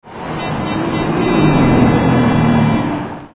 annoying car horn every three seconds.
granturismobeep.mp3